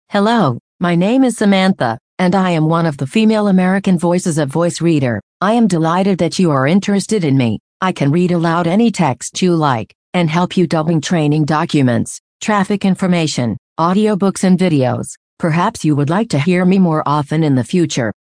Voice Reader Home 22 English (American) - Female voice [Samantha]
Voice Reader Home 22 ist die Sprachausgabe, mit verbesserten, verblüffend natürlich klingenden Stimmen für private Anwender.